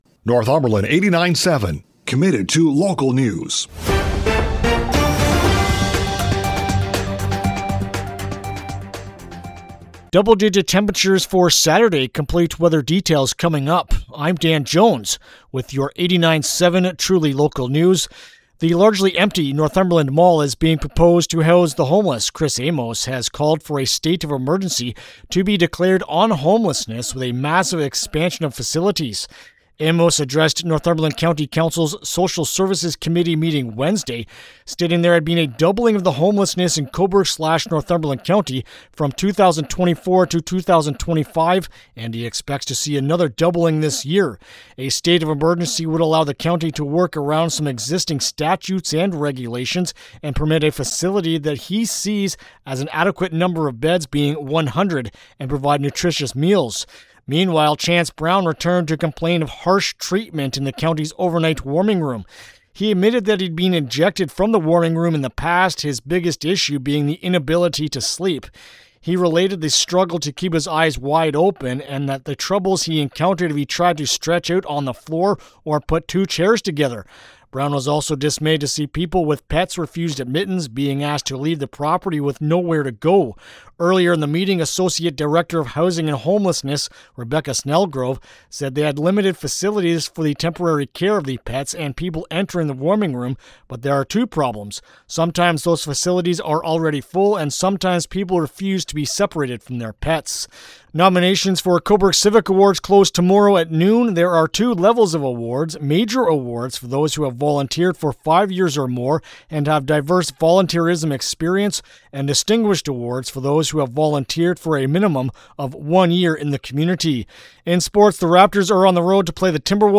Thurs.-March-5-AM-News-2.mp3